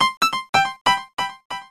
SAMPLES : Piano
piano nē 17
piano17.mp3